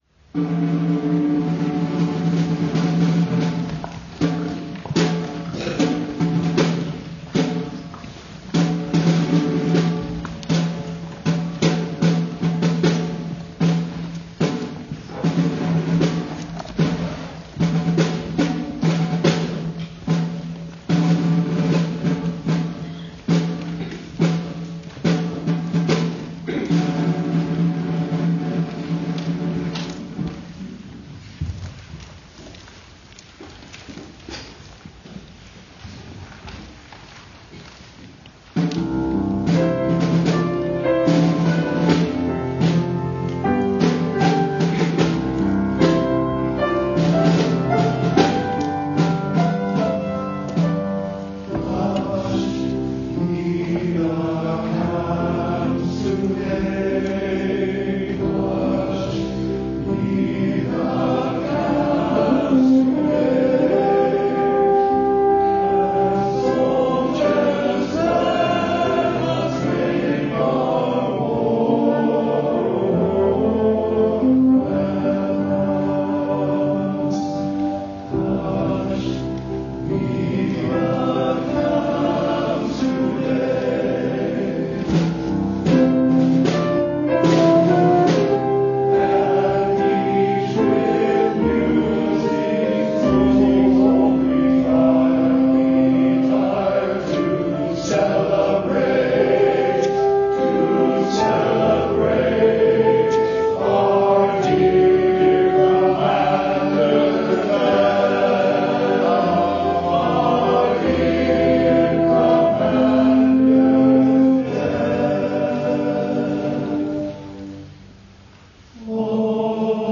Choir and Instrumental Music
Hushed be the Camp sung by the men in choir (11/11/18)